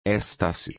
I’ve doubled the “s” in an attempt to lengthen the initial vowel: